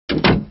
دانلود صدای در 4 از ساعد نیوز با لینک مستقیم و کیفیت بالا
جلوه های صوتی
برچسب: دانلود آهنگ های افکت صوتی اشیاء دانلود آلبوم صدای باز و بسته شدن درب از افکت صوتی اشیاء